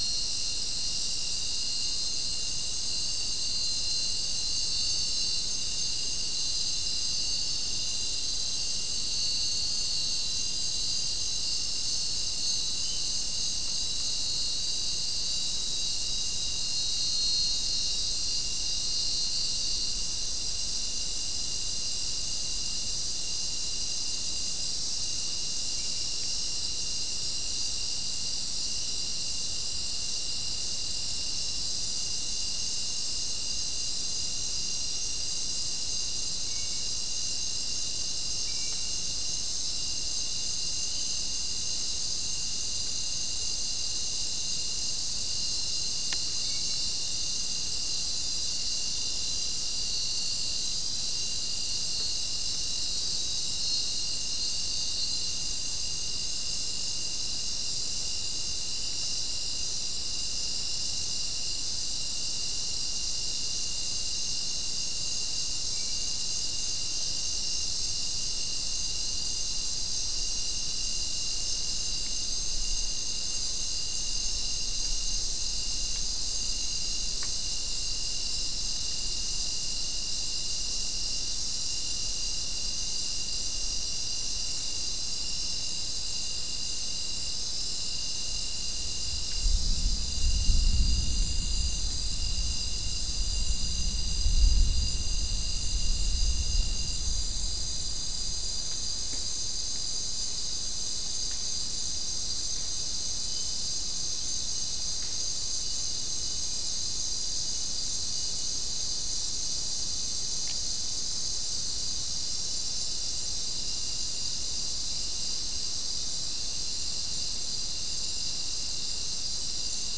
Non-specimen recording: Soundscape Recording Location: South America: Guyana: Sandstone: 2
Recorder: SM3